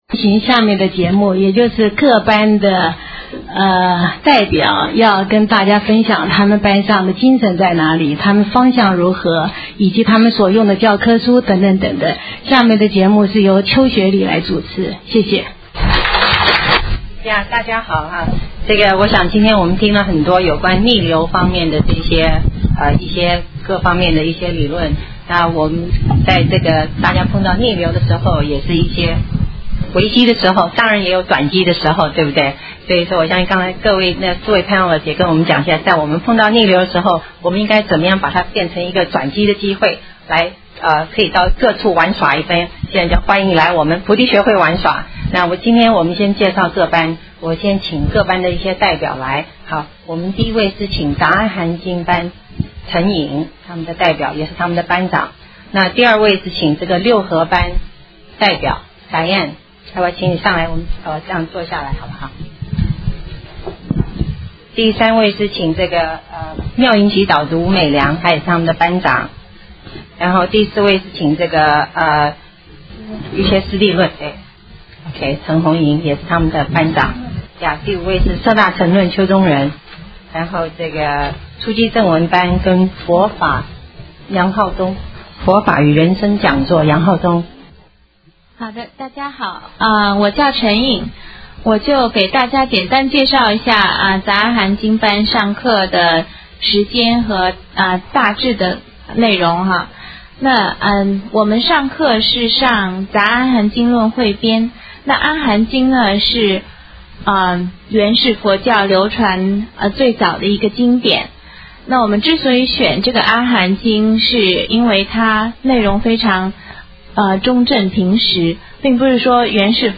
MP3 files for Dharma Talks